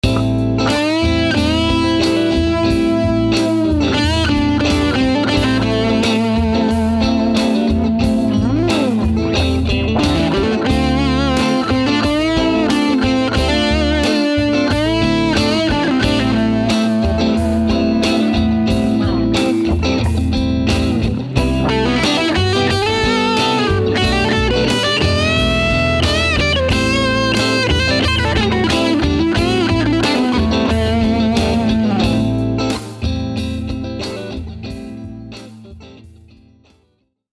I randomly picked a ’57 Deluxe Dual Mic, then started to strum this little ditty in Am. Before I knew it, I was adding drum and bass tracks, to record the riff.
In the end, I wanted to get a sample out, so I chose a ’59 Bassman with a Fender Fuzz-Wah plug-in to get some fuzz, then recorded the following sound bite:
I don’t know about you, but I really can’t tell the difference between the real thing and software.